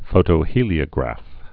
(fōtō-hēlē-ə-grăf)